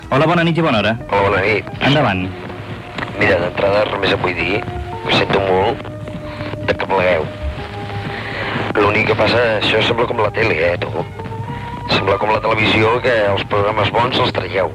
Trucada d'un oïdor el dia que el programa acabava.